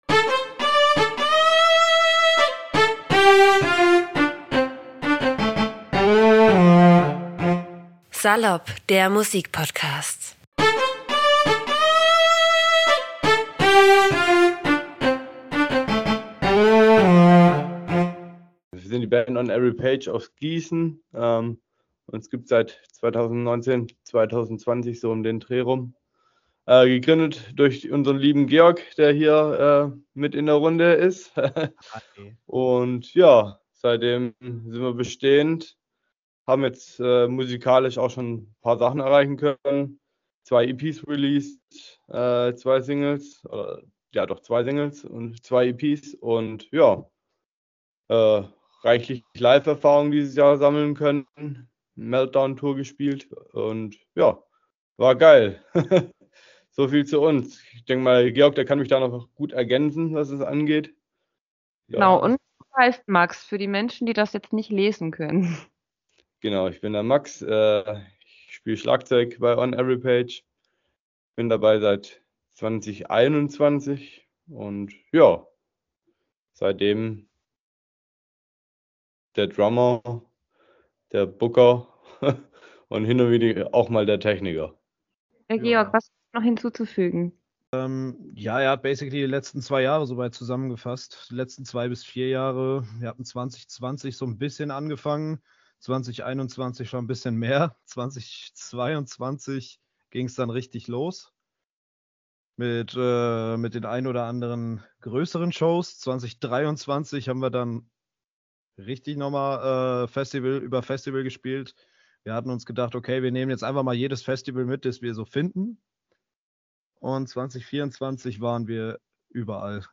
on-every-page-im-interview-mmp.mp3